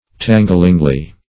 tanglingly - definition of tanglingly - synonyms, pronunciation, spelling from Free Dictionary Search Result for " tanglingly" : The Collaborative International Dictionary of English v.0.48: Tanglingly \Tan"gling*ly\, adv.